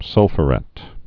(sŭlfə-rĕt, -fyə-)